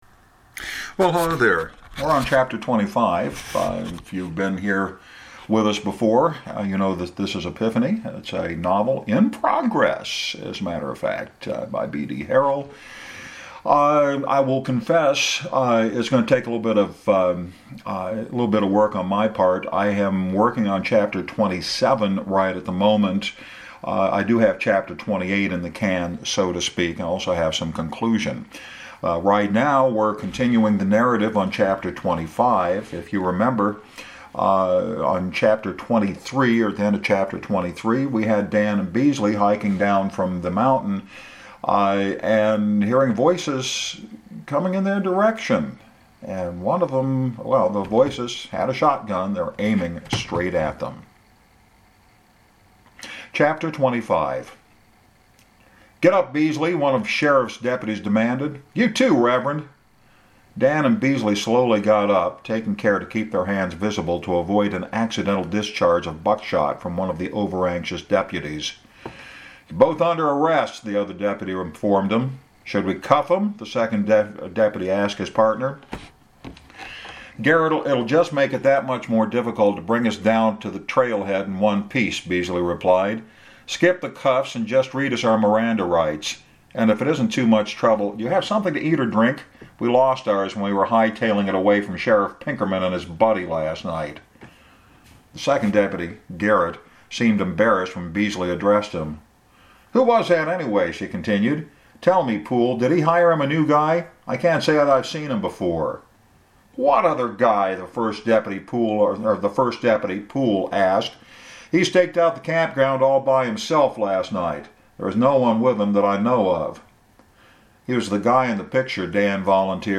Today’s chapter is a little shorter with a few vocal fumbles here and there.